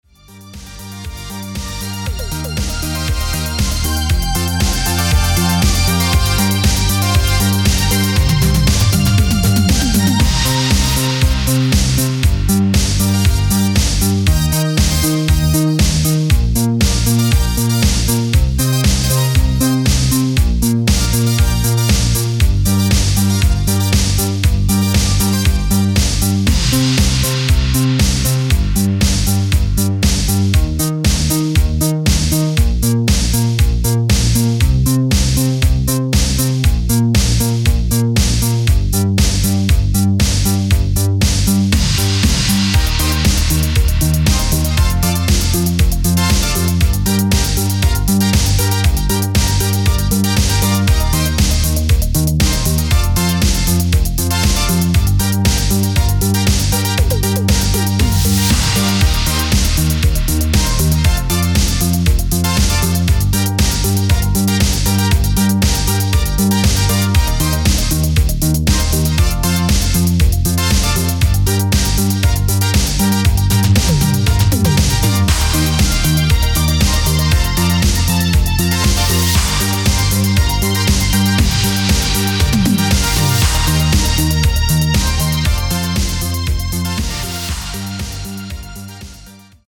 Italo Disco